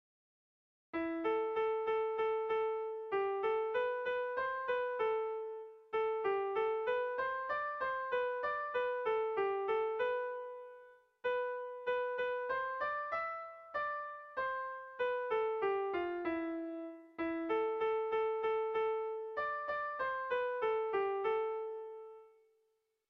Melodías de bertsos - Ver ficha   Más información sobre esta sección
Kontakizunezkoa
Zortziko txikia (hg) / Lau puntuko txikia (ip)
ABDE